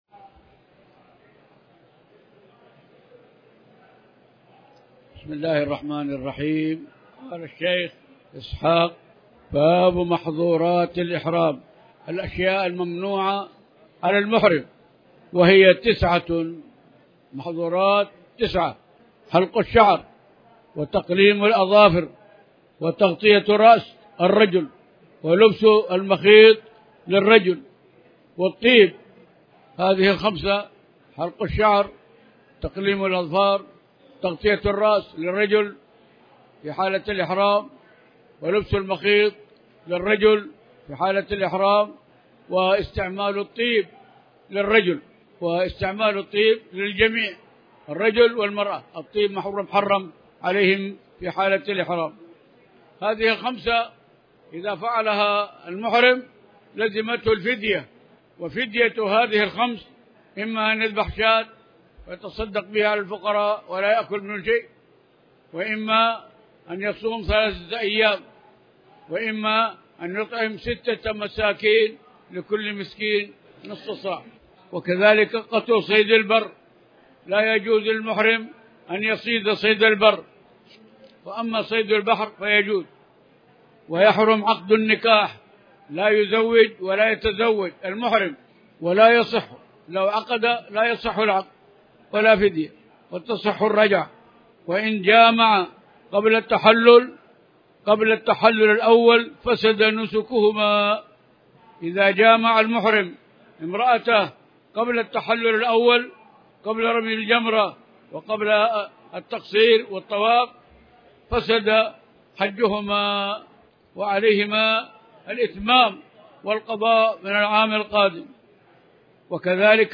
تاريخ النشر ٣ ذو الحجة ١٤٣٩ هـ المكان: المسجد الحرام الشيخ